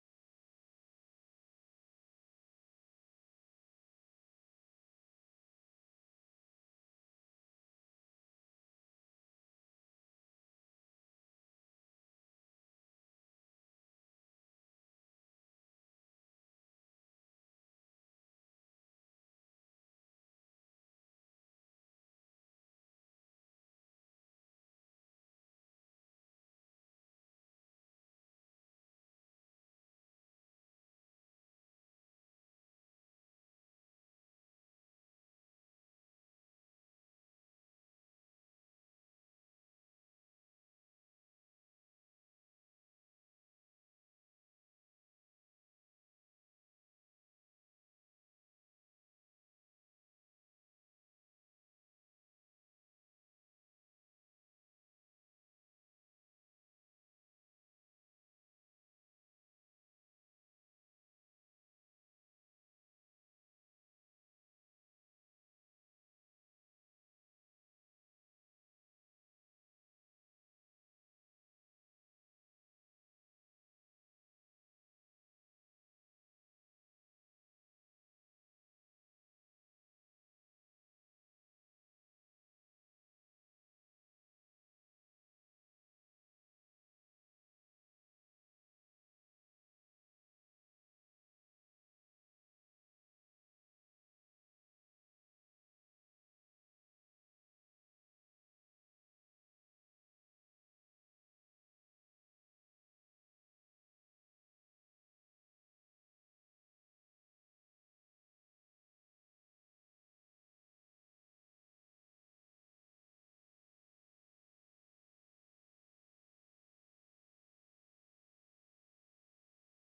15 ottobre 2025 I cittadini che desiderano partecipare alla seduta di Consiglio del 15 ottobre 2025 alle ore 19:00 presso la sede del Municipio 7, in via Anselmo da Baggio 55, devono...